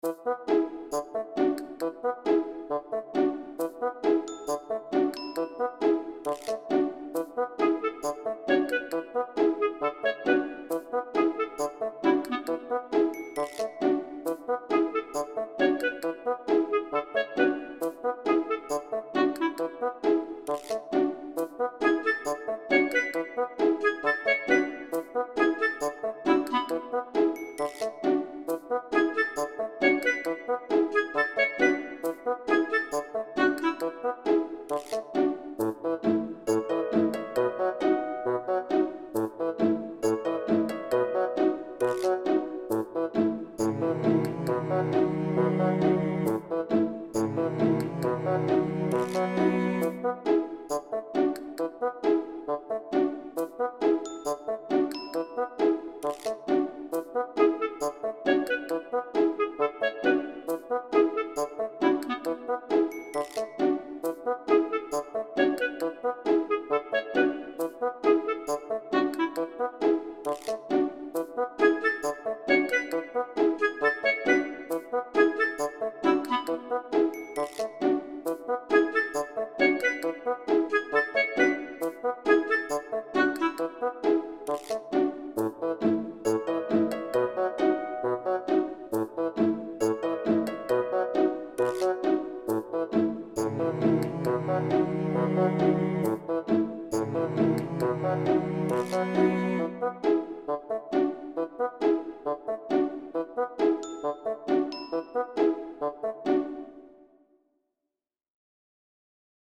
Cinematic
A light and playful tune for the young-at-heart.